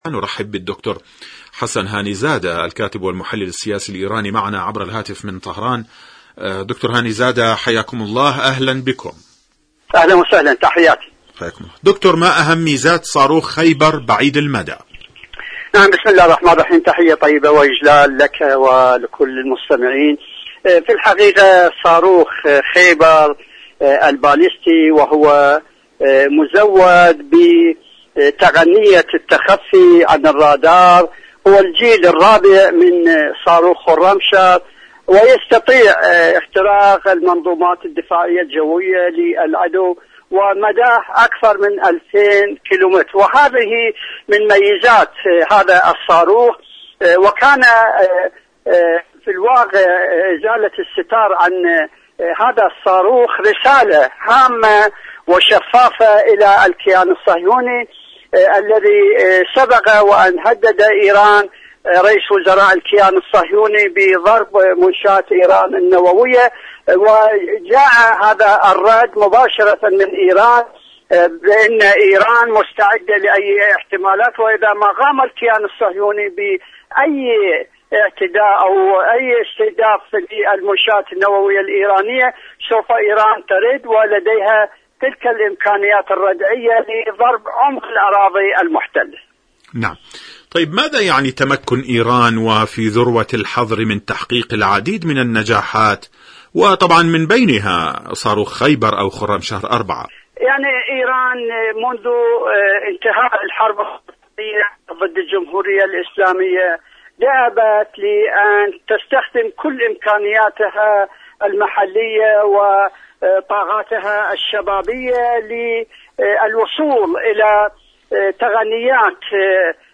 مشاركة صوتية برنامج ايران اليوم المشهد السياسي
مشاركة هاتفية